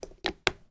Закрыли крышку духов парфюмерной воды